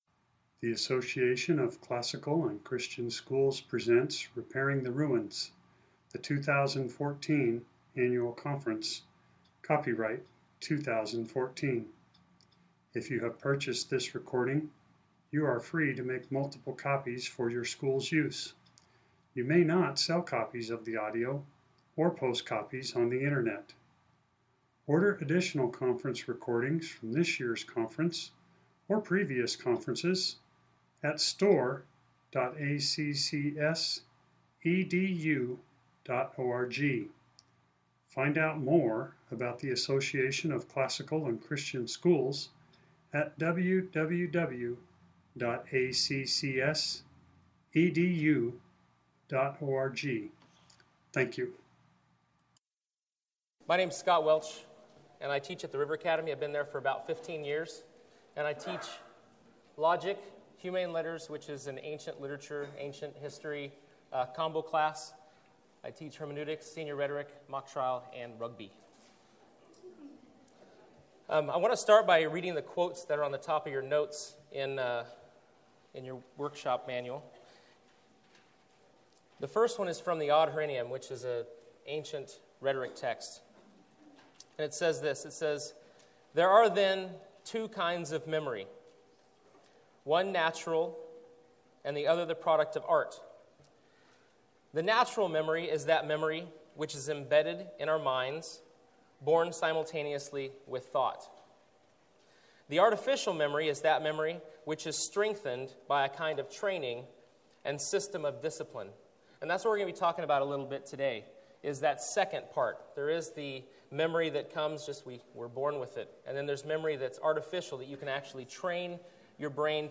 2014 Workshop Talk | 1:03:03 | All Grade Levels, Leadership & Strategic
The Association of Classical & Christian Schools presents Repairing the Ruins, the ACCS annual conference, copyright ACCS.